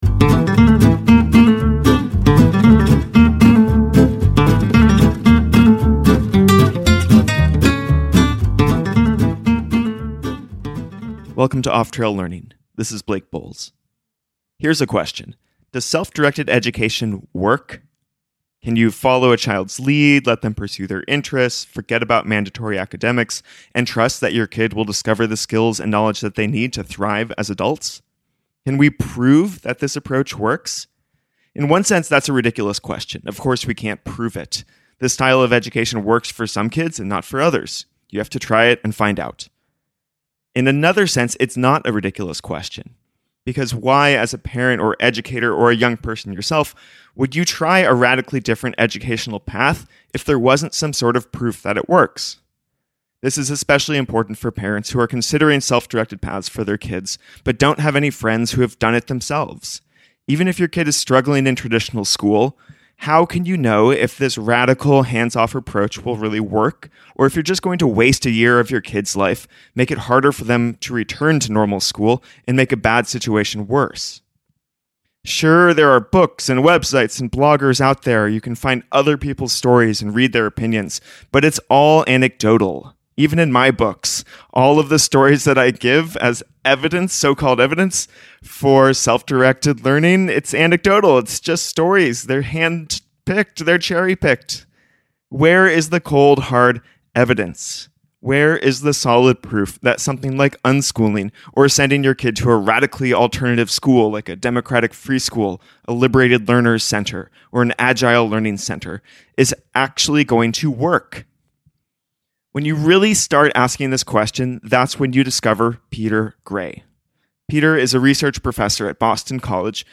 In this episode I speak with Peter Gray, Ph.D., about the evidence (and lack thereof) for the effectiveness of unschooling and democratic free schools. Topics include: How does one measure the "effectiveness" of education in the first place?